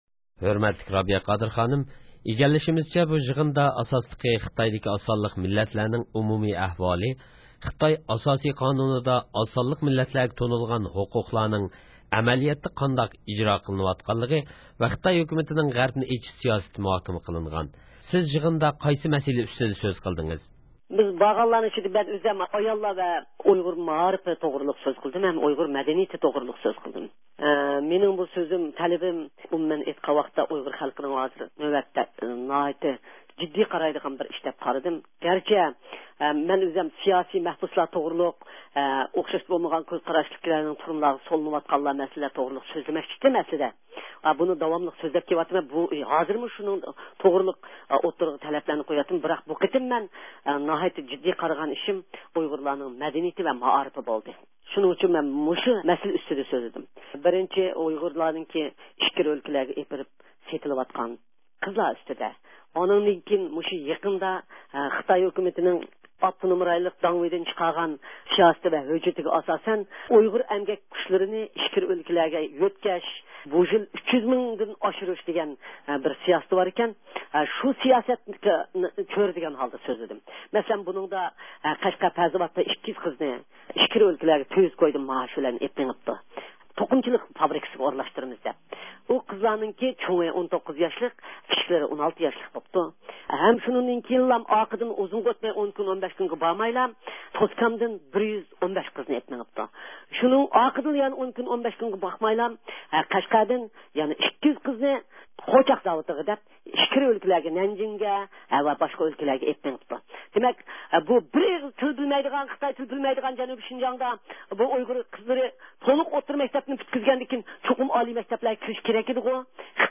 نيۇ-يورك شەھىرىدە ئۆتكۈزۈلگەن يېغىن ھەققىدە رابىيە قادىر بىلەن سۆھبەت – ئۇيغۇر مىللى ھەركىتى